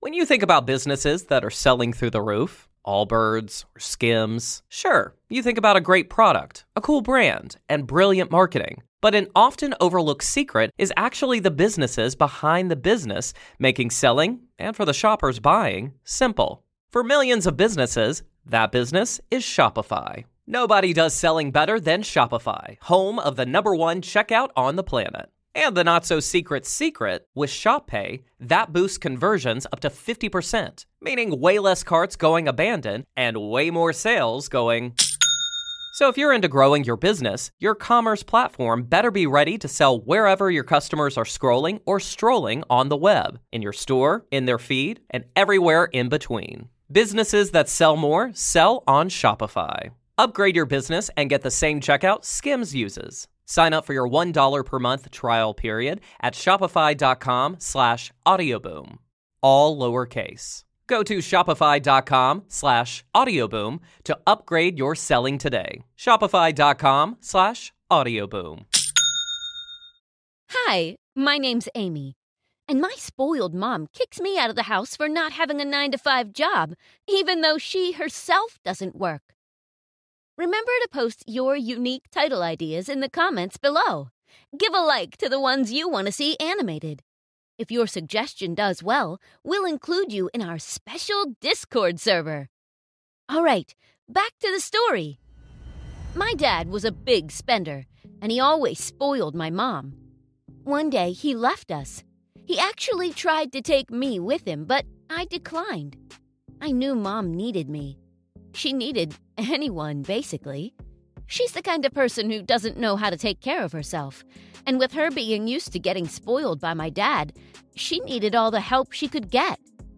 This animated podcast story is a male story teller